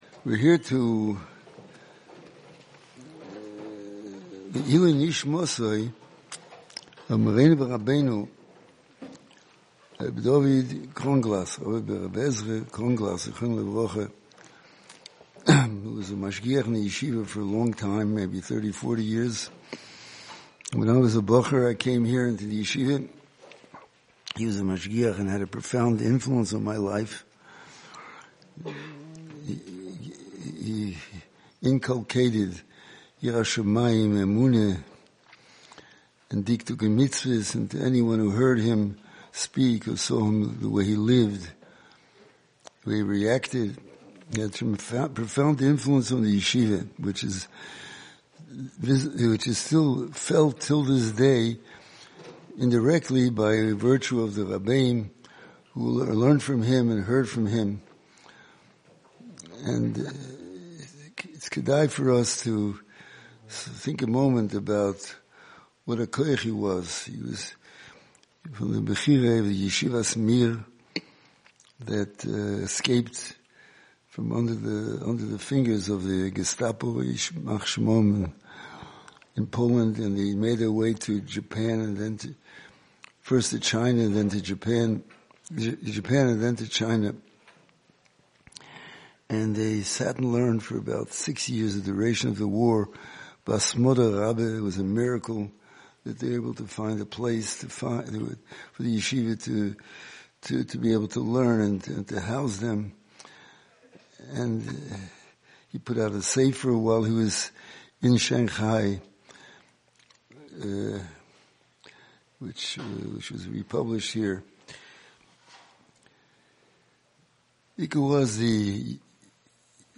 Liluy nishmaso, the Yeshiva held a special program in the Yeshiva Bais Hamedrash